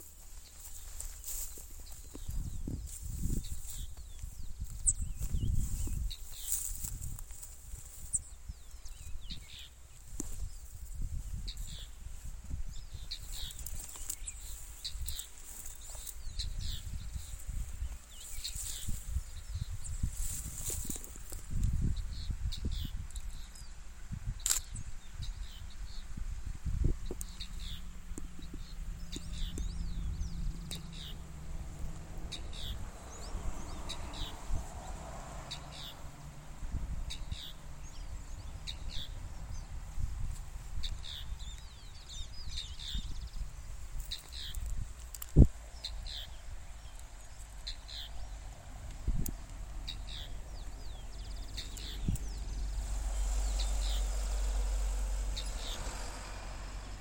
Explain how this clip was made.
Country: Argentina Detailed location: Ruta 307 entre Tafí del Valle y El Infiernillo Condition: Wild